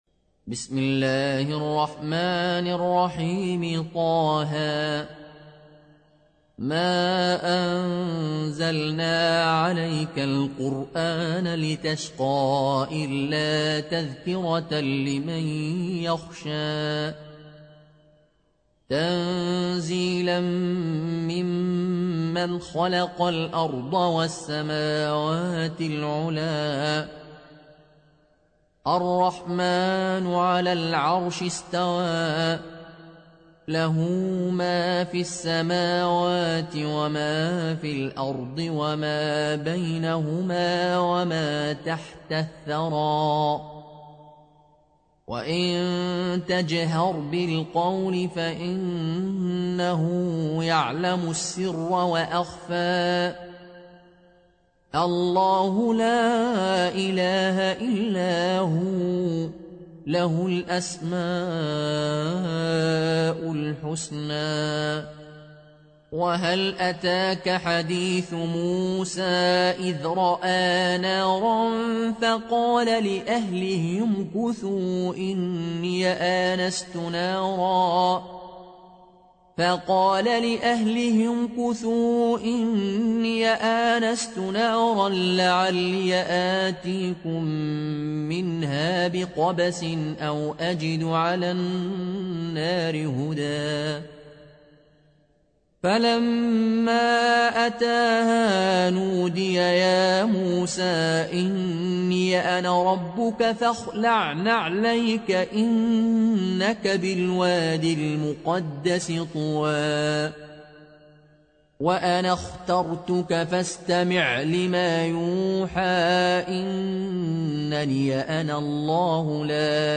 (Riwayat Qaloon)